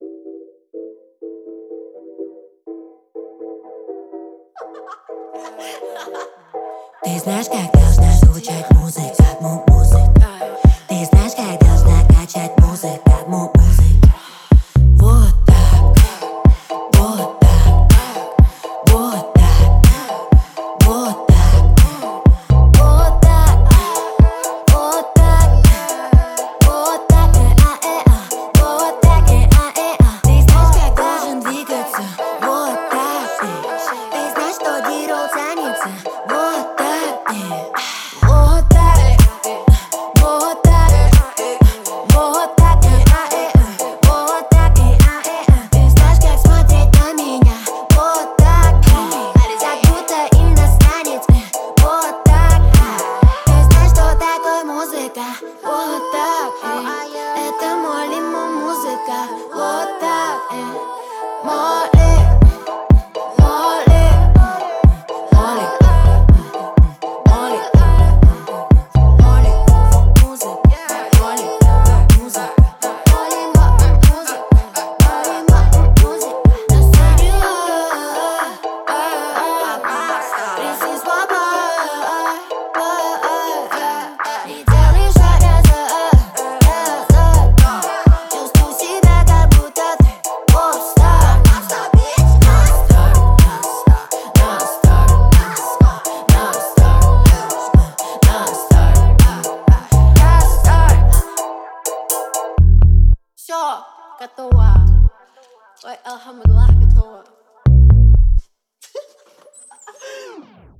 Звучание трека отличается запоминающимся ритмом и мелодией